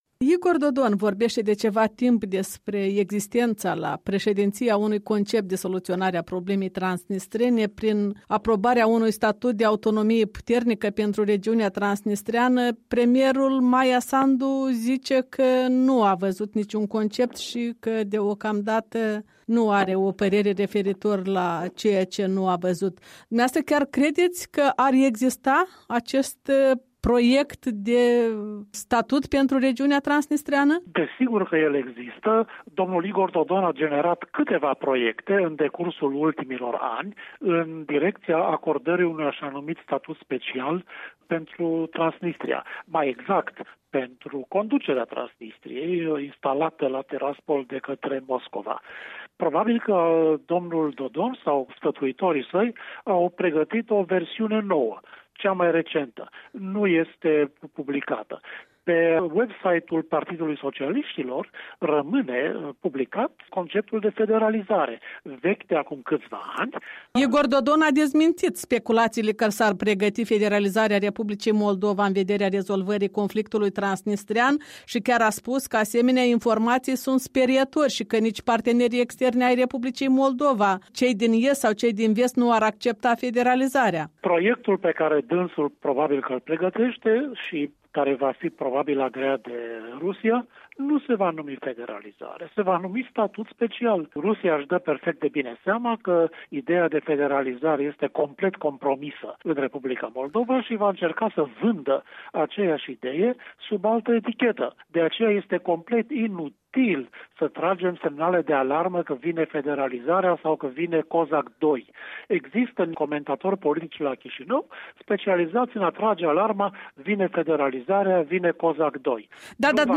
Un interviu cu analistul politic